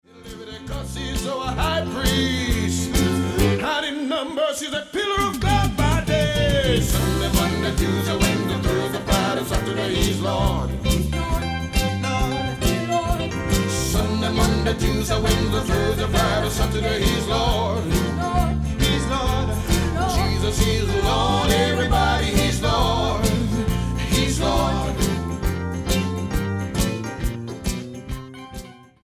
STYLE: Reggae